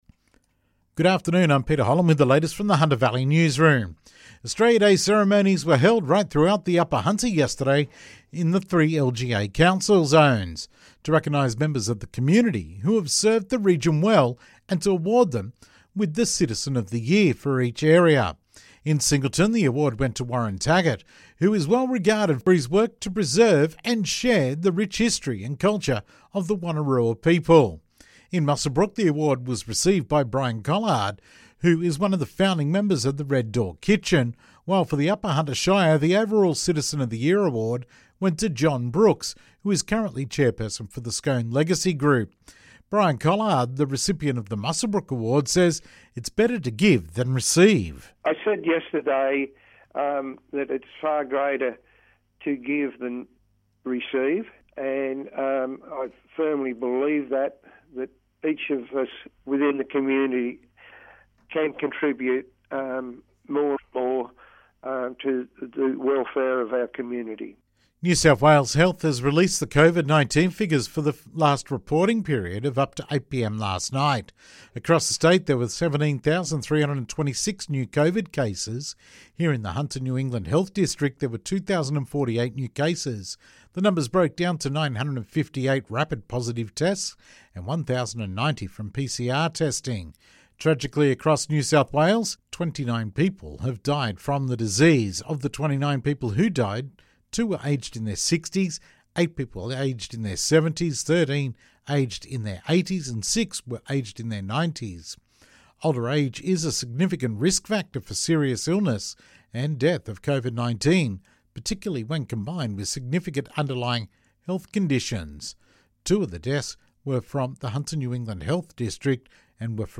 Hunter Valley afternoon news headline Thursday the 27th January 2022